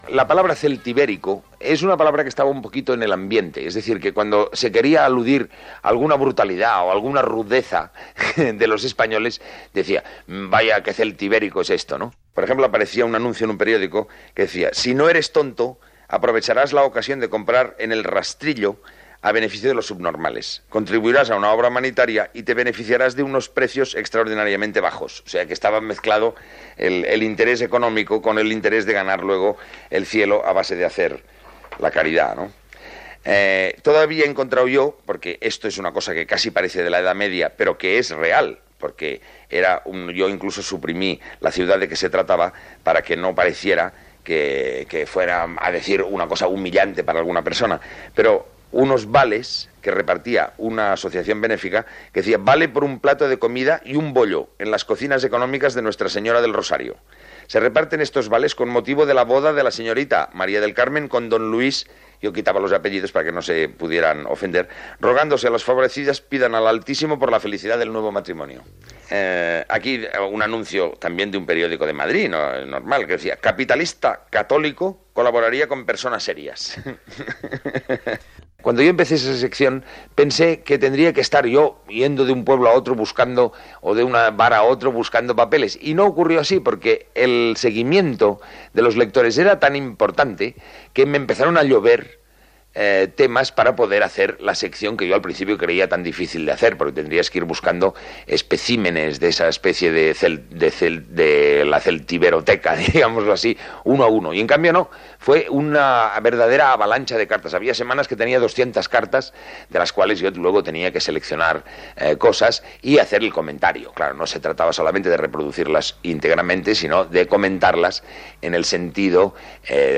Fragment extret del programa "Audios para recordar" de Radio 5 emès el 9 d'octubre del 2017.